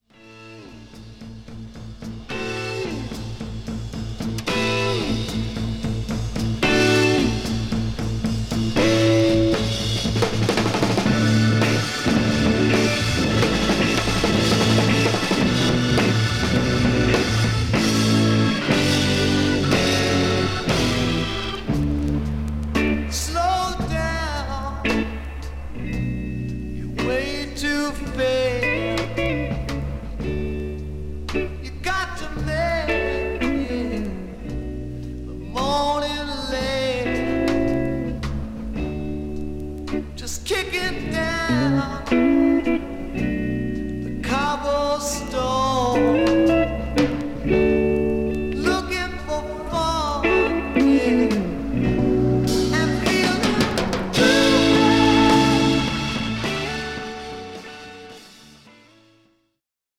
1968年、サンフランシスコでのライブを収録した2枚組。